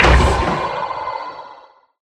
Sound / Minecraft / mob / irongolem / death.ogg
death.ogg